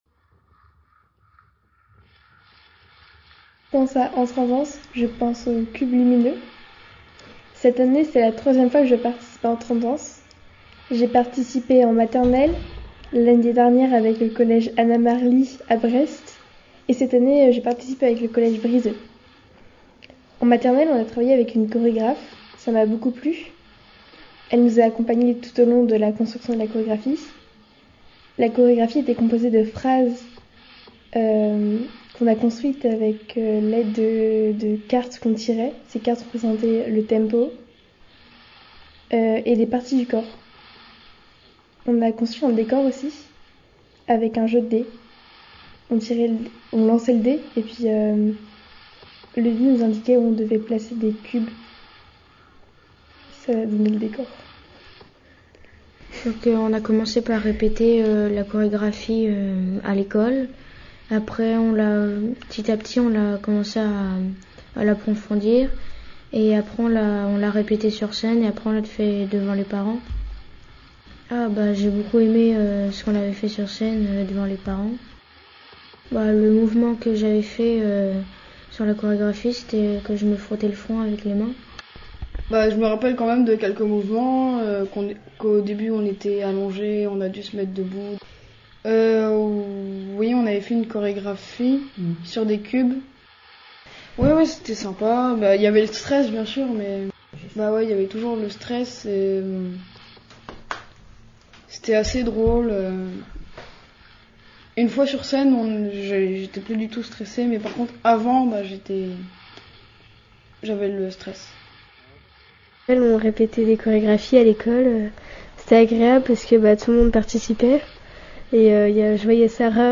Genre : Vocal